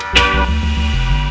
RIFFGTRFX1-L.wav